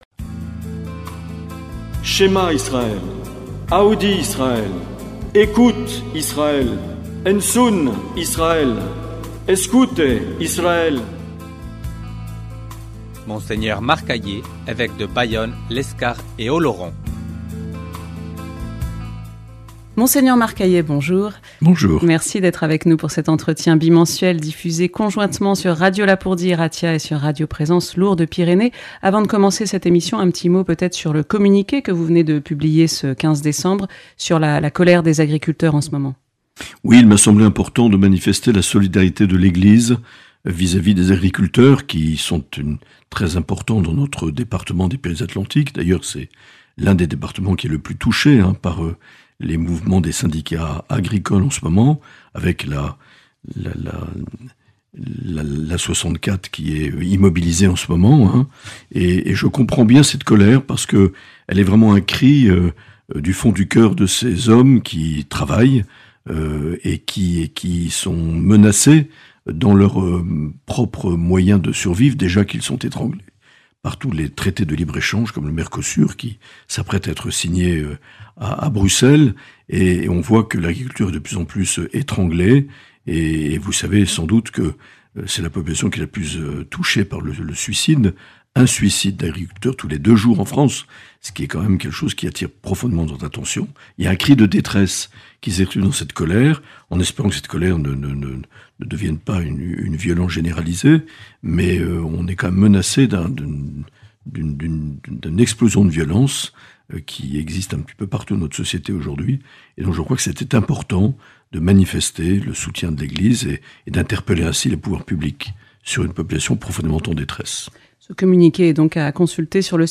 L’entretien avec Mgr Marc Aillet - Décembre 2025